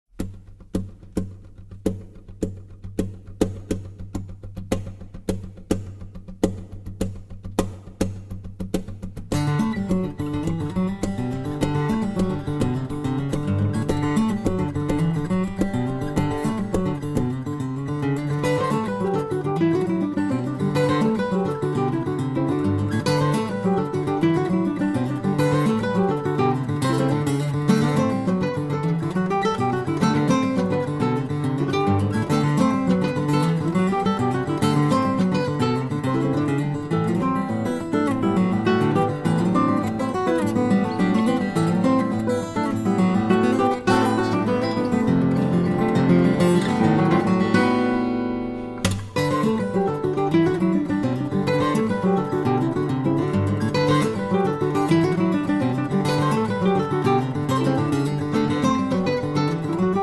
Guitar Duo